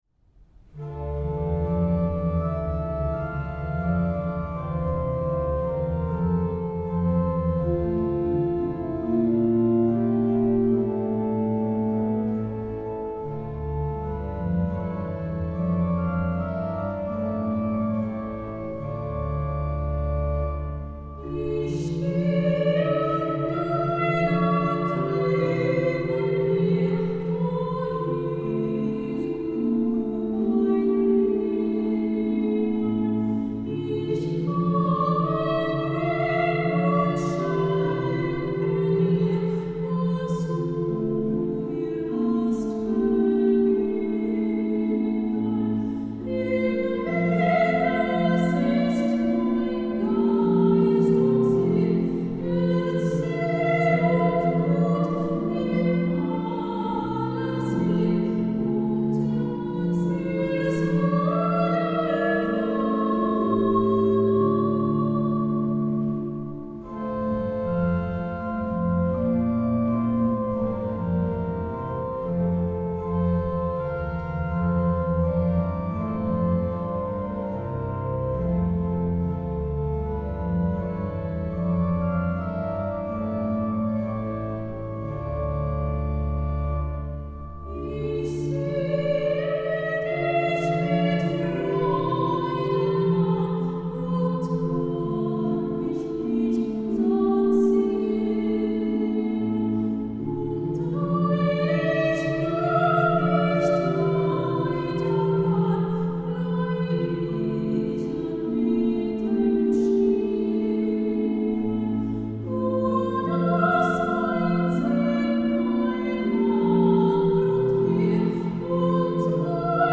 Jahresschlussgottesdienst 2012
Sopran
Orgel